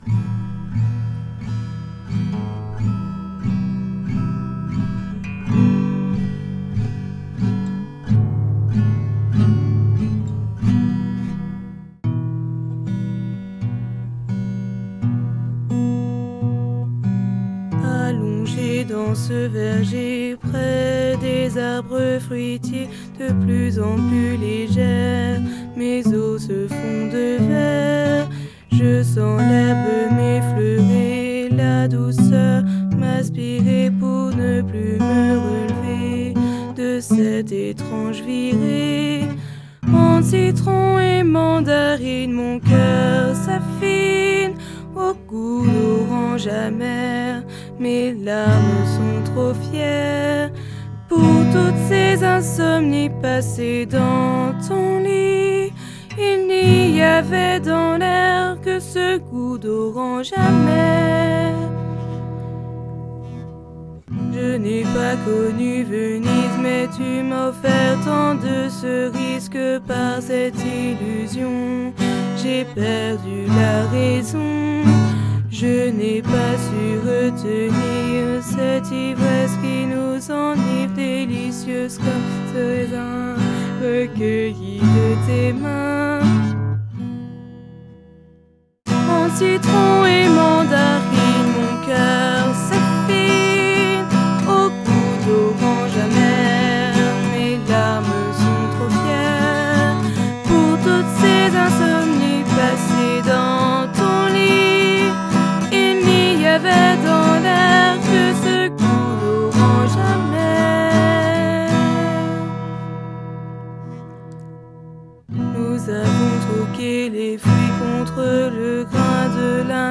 2008, Soupe pop pour radio